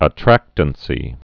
(ə-trăktən-sē) also at·trac·tance (-təns)